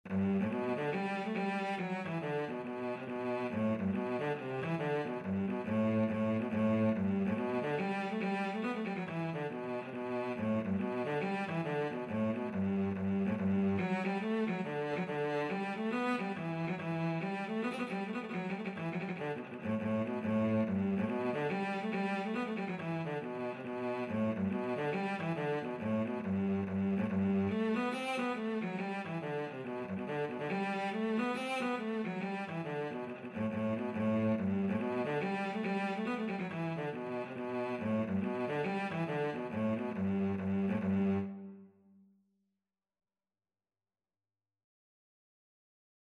G3-C5
4/4 (View more 4/4 Music)
G major (Sounding Pitch) (View more G major Music for Cello )
Cello  (View more Easy Cello Music)
Traditional (View more Traditional Cello Music)